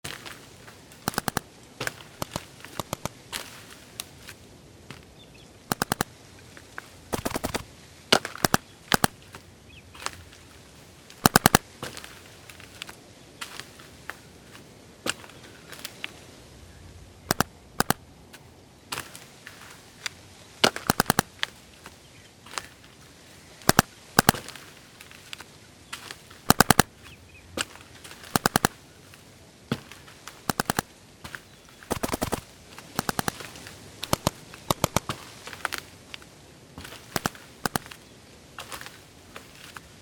Paintball-field-ambience-paintball-guns-3.mp3